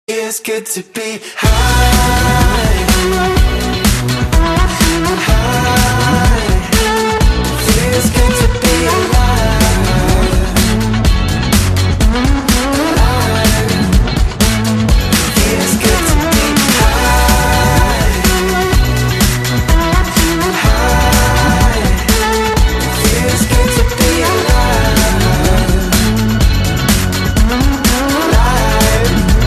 M4R铃声, MP3铃声, 欧美歌曲 50 首发日期：2018-05-13 11:38 星期日